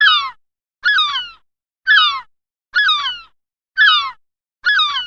Möwengeschrei klingelton kostenlos
Kategorien: Tierstimmen